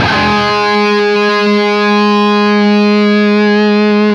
LEAD G#2 CUT.wav